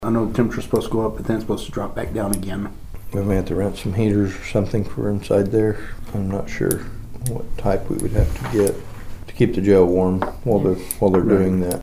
Commissioners Paul Crupper and Troy Friddle discussed how the prisoners need to stay warm during the project.